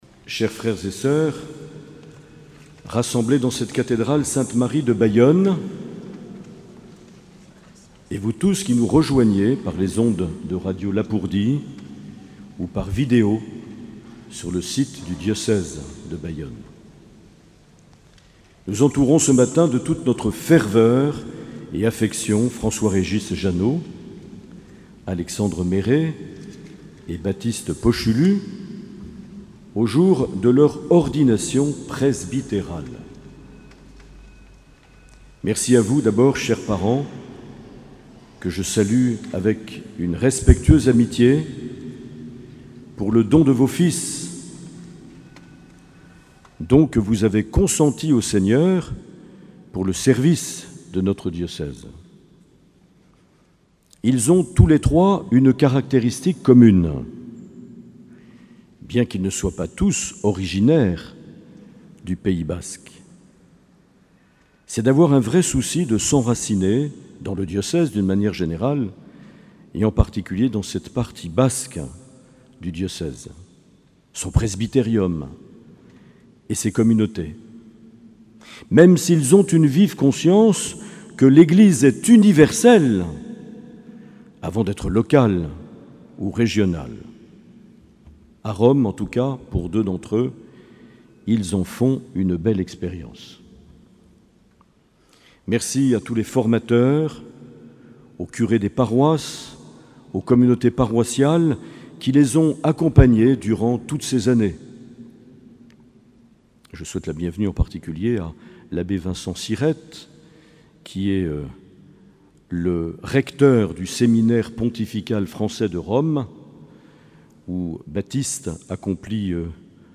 Accueil \ Emissions \ Vie de l’Eglise \ Evêque \ Les Homélies \ 26 juin 2020 - Cathédrale de Bayonne - Ordinations sacerdotales (...)
Une émission présentée par Monseigneur Marc Aillet